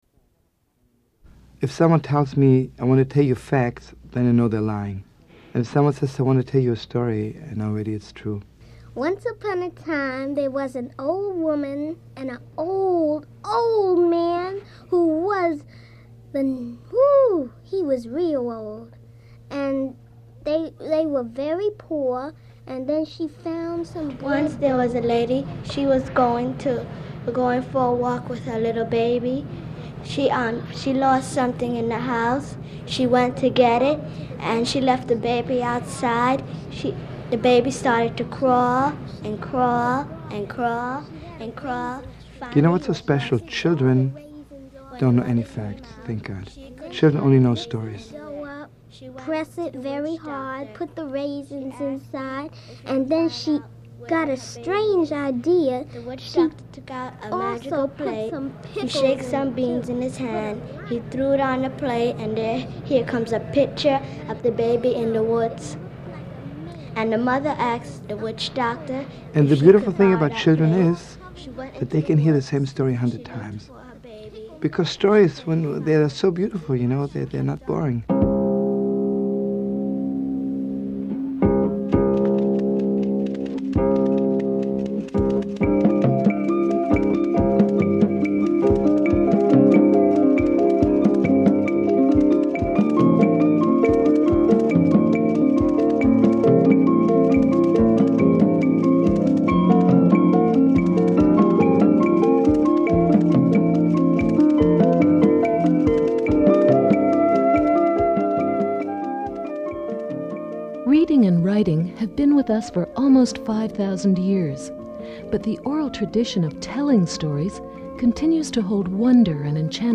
This introduction to the documentary -- and the series -- begins with fragments of children telling stories and comments from Rabbi Schlomo Karlbach about the truthfulness of stories.
Anthropologist and folklorist, Joseph Campbell, talks about the roots of myths and storytelling.